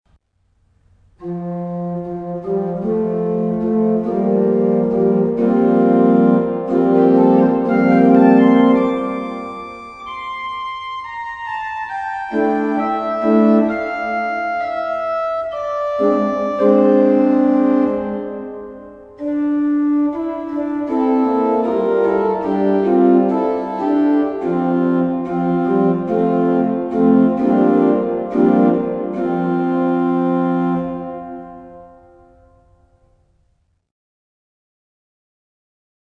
Orgelvorspiele
Hier finden Sie einige Orgelvorspiele zu Liedern aus dem Gotteslob.
gl-490_was_uns-die_erde_orgelvorspiel.mp3